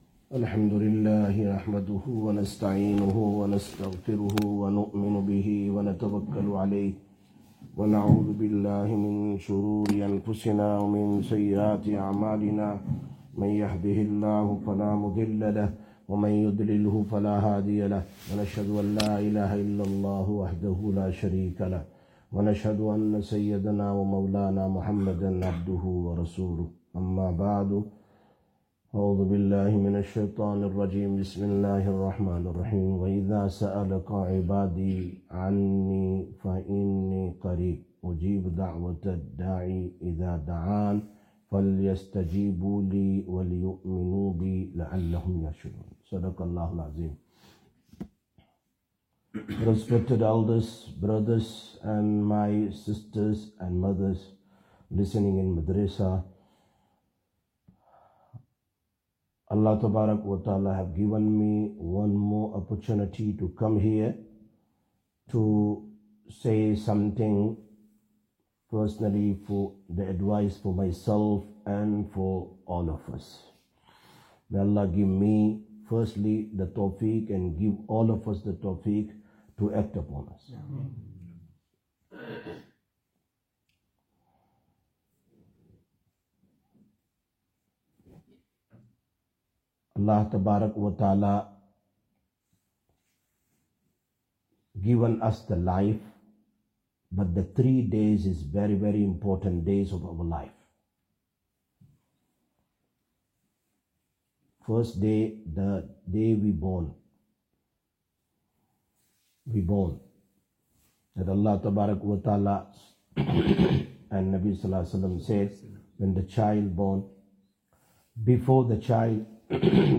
23/03/2025 Masjid Ur Rashideen Bayaan